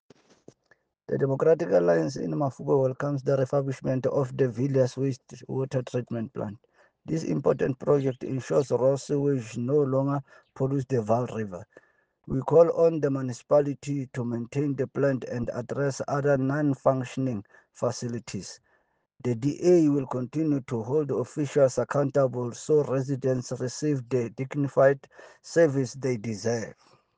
Sesotho soundbites by Cllr Fako Tsotetsi.